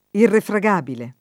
[ irrefra g# bile ]